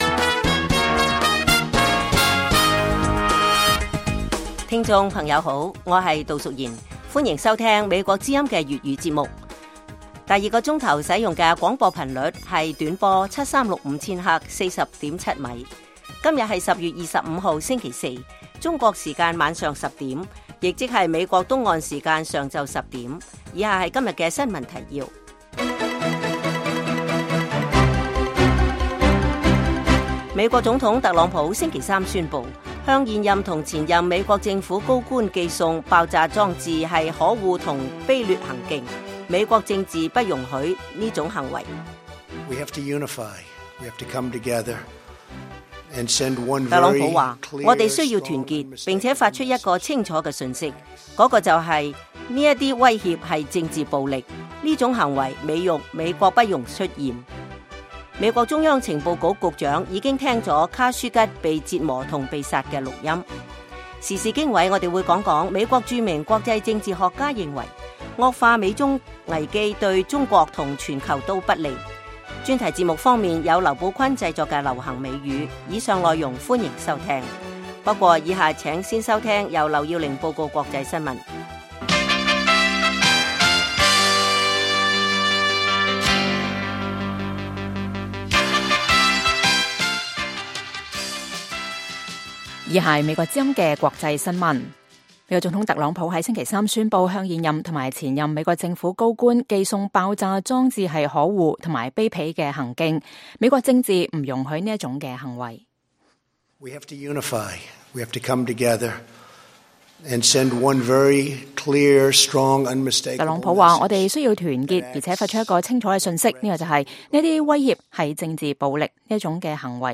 北京時間每晚10－11點 (1400-1500 UTC)粵語廣播節目。內容包括國際新聞、時事經緯、英語教學和社論。